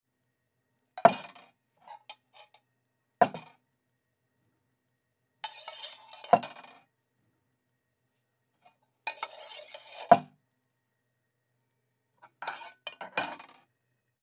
Звуки бидона
Звук бидона с молоком на столе